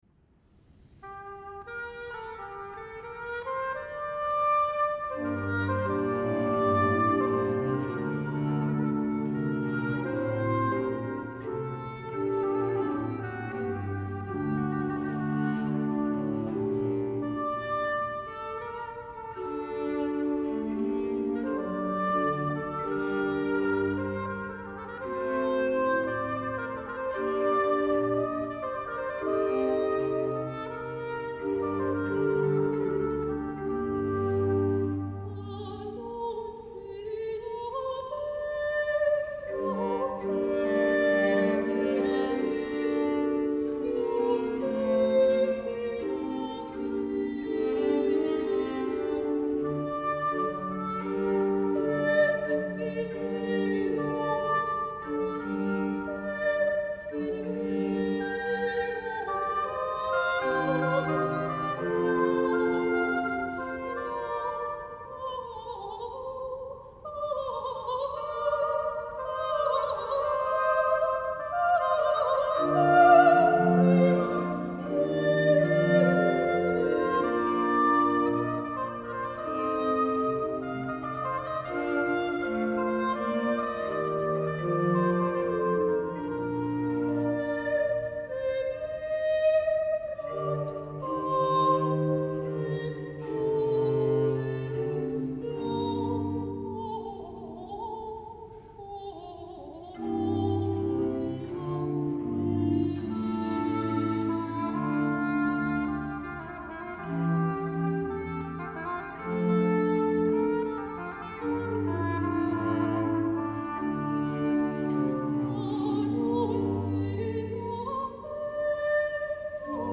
Récit – Soprano